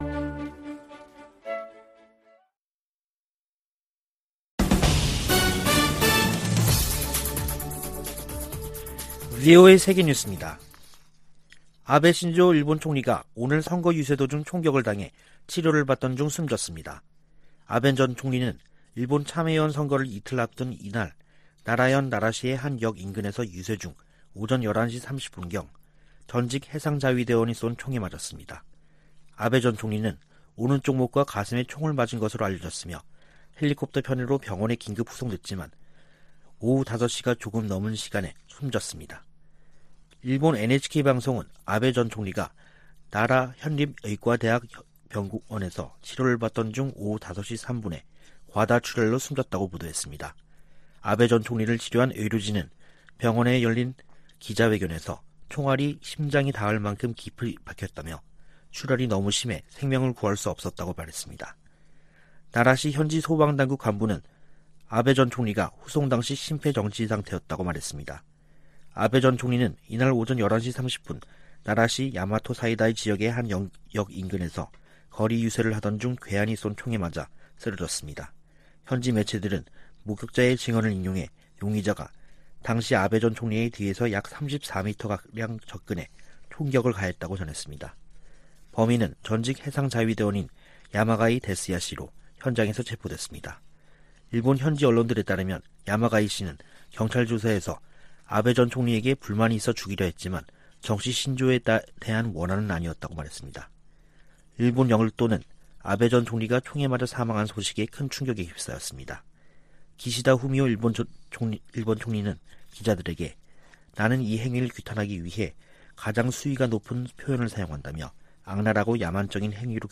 VOA 한국어 간판 뉴스 프로그램 '뉴스 투데이', 2022년 7월 8일 2부 방송입니다. 미국과 한국, 일본이 G20 외교장관회의를 계기로 3국 외교장관 회담을 열고 북한 문제 등을 논의했습니다. 미의회에서는 북핵 문제를 넘어 미한일 공조를 강화하려는 움직임이 두드러지고 있습니다.